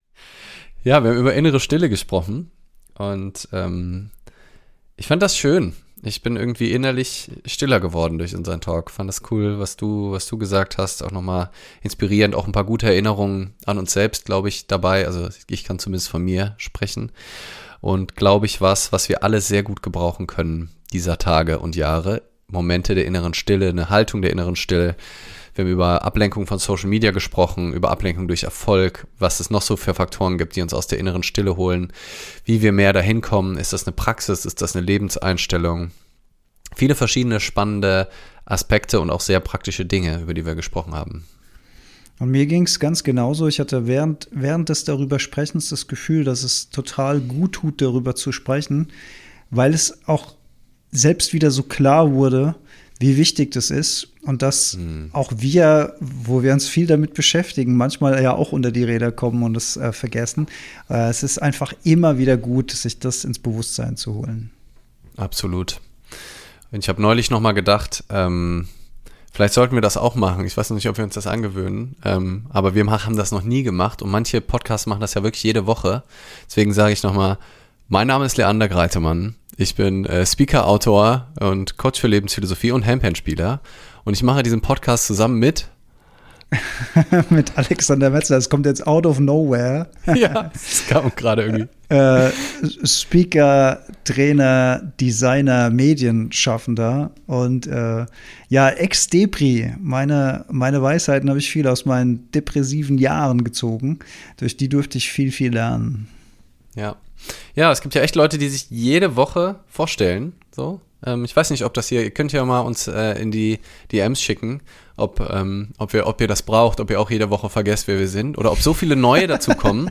Folge 68 - Insta live vom 25.03.24